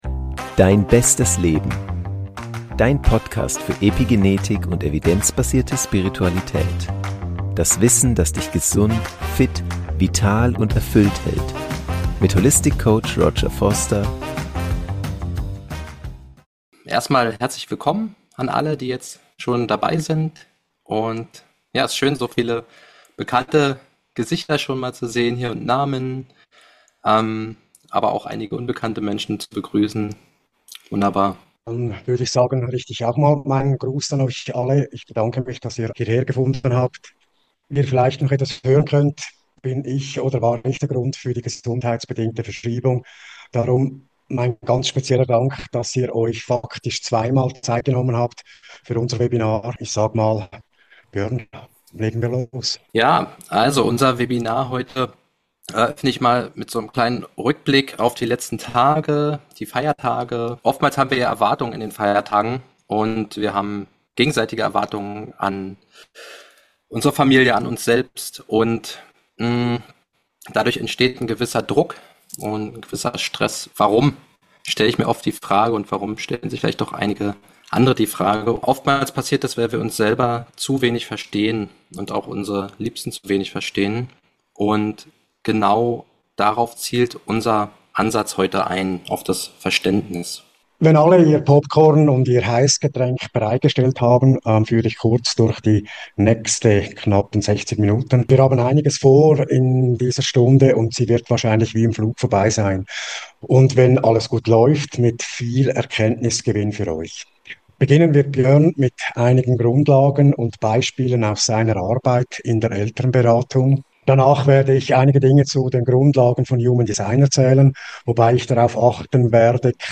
Mit einem berührenden Live-Reading (Vater & Tochter), vielen Praxisbeispielen und einem klaren Perspektivwechsel für Eltern, die ihr Kind nicht „formen“, jedoch wirklich sehen wollen.